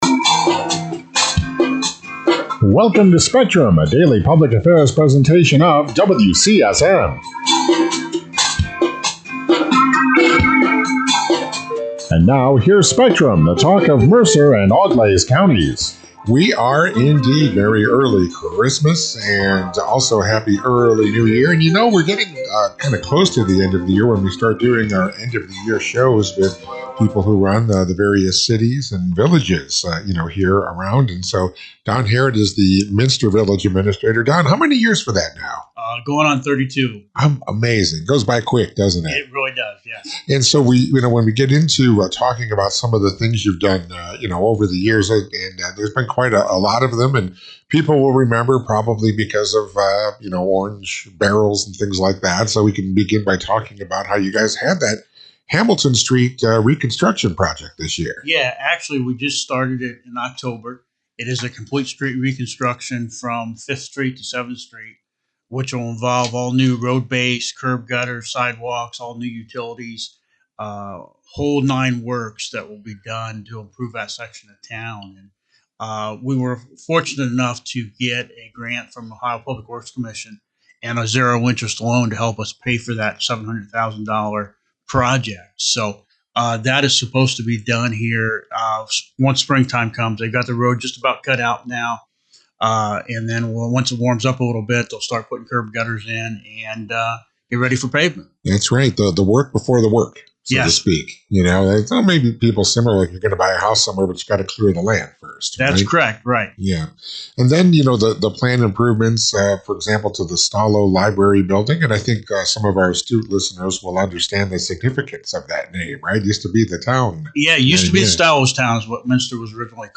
Minster Village Administrator Don Harrod Recaps 2025 Projects and Previews 2026 Priorities on Spectrum To hear the show: Click Here Minster Village Administrator Don Harrod says 2025 was another busy year for the village, with major work underway on streets, parks, utilities, public safety communications, and downtown development. During a year end interview on Spectrum, Harrod said the Hamilton Street project is a full reconstruction from 5th Street to 7th Street, including a new road base, curb and gutter, sidewalks, and new utilities.